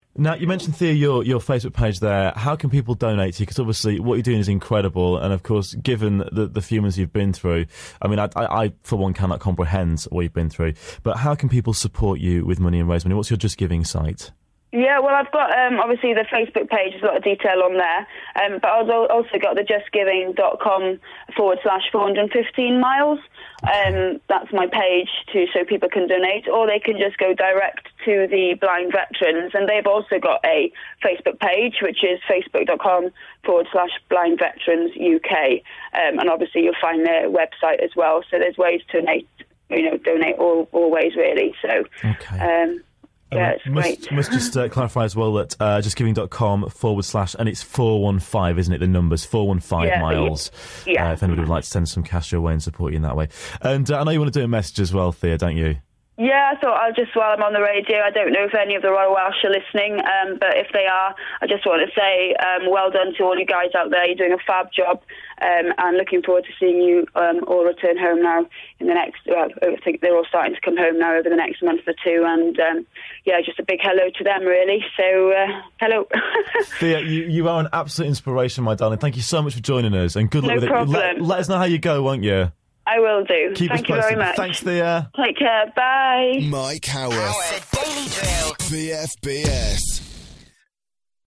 415 Miles - BFBS Interview Part Three